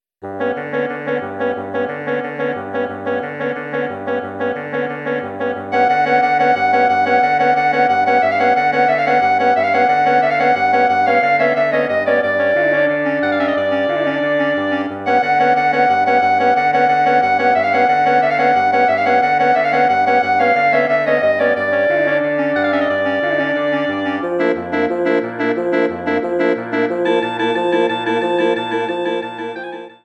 Bearbeitung für Bläserquintett
Besetzung: Flöte, Oboe, Klarinette (B), Horn (F), Fagott
Arrangement for woodwind quintet